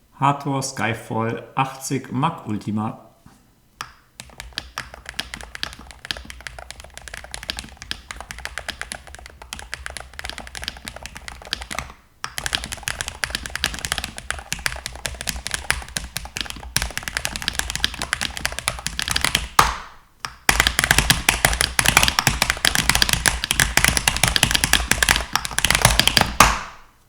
Hator Skyfall Mag 80 Ultima Wireless (Hator Aurum Ice Magnetic)
Sie sind klar akzentuiert und „ticken“ beim Aufprall. Das soll nicht heißen, dass die Tastatur laut ist: Sie generiert ein wahrnehmbares Geräusch, das im Alltag gut an den Rand tritt.